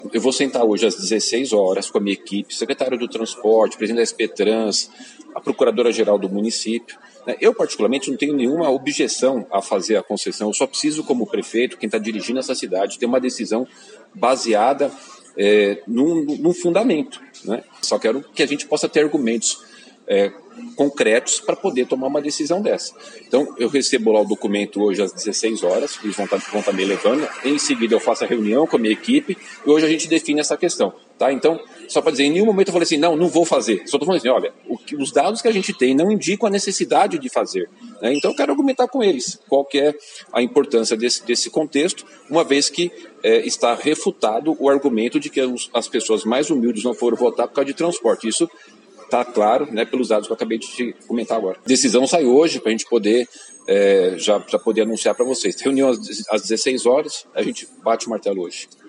Ouça a entrevista do prefeito Ricardo Nunes neste link:abaixo: